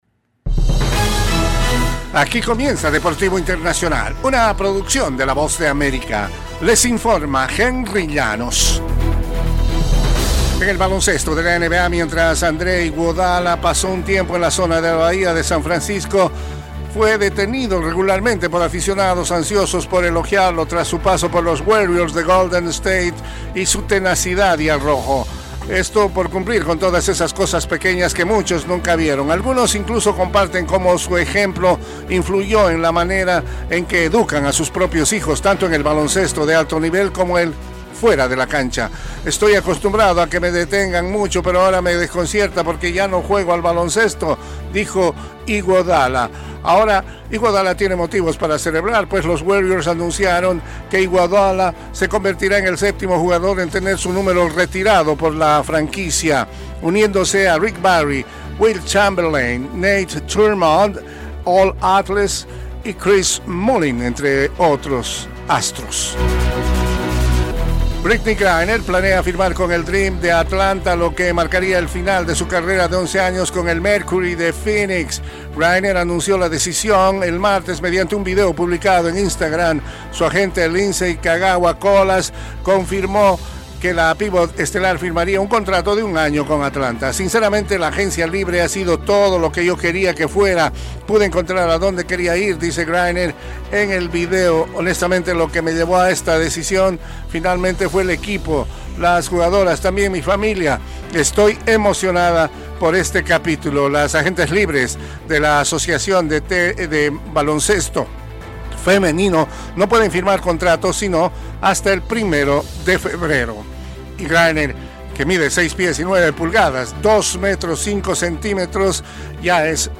Las noticias deportivas llegan desde los estudios de la Voz de América